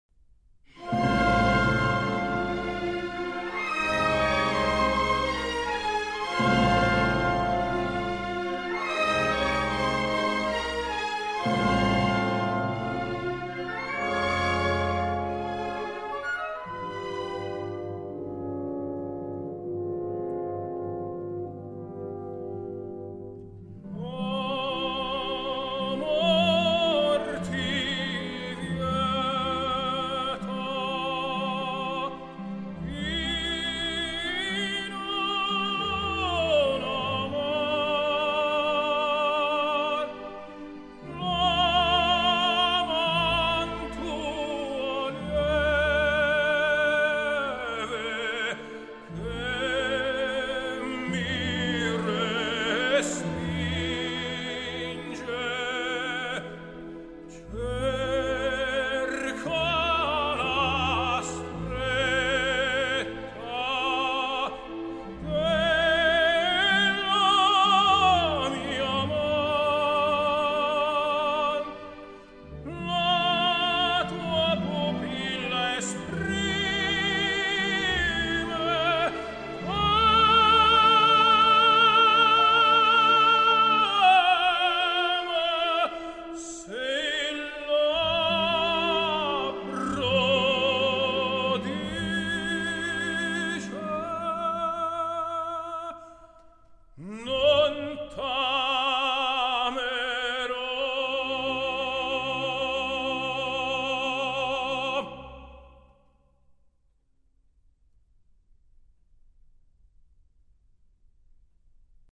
类别：古典音乐